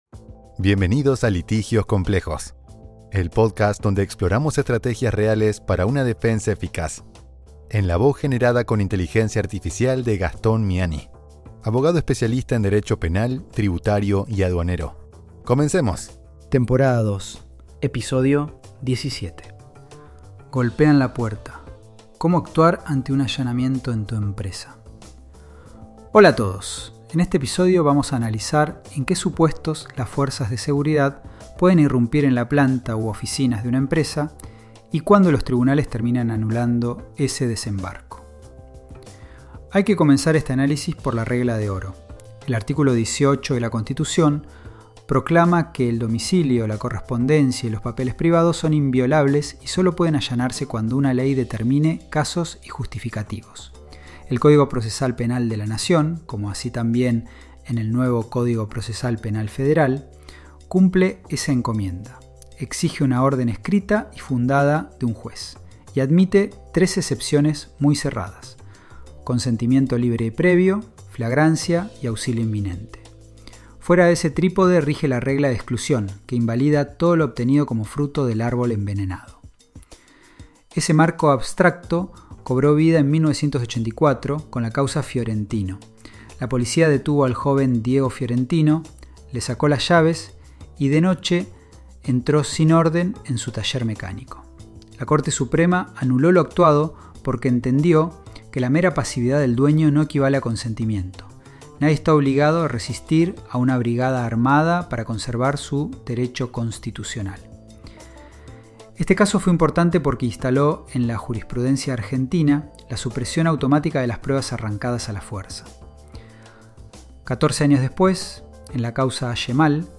Mediante una Voz generada con Inteligencia Artificial